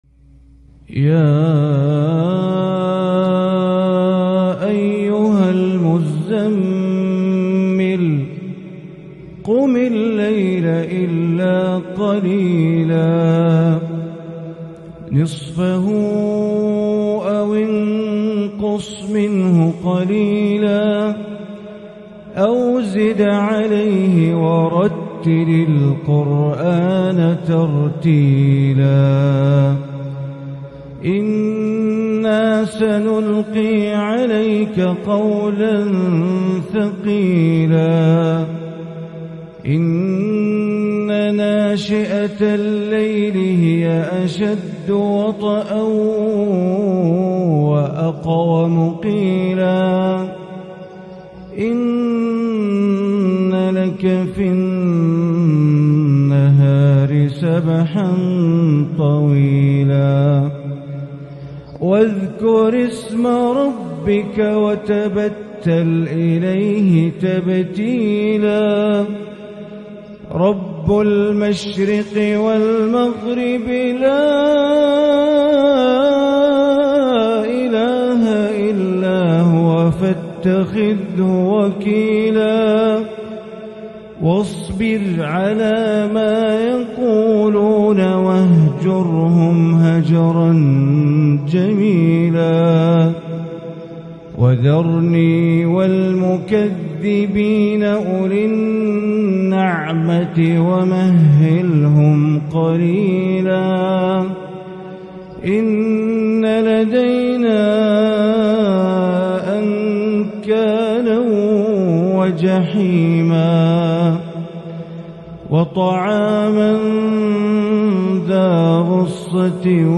سورة المزمل > مصحف الحرم المكي > المصحف - تلاوات بندر بليلة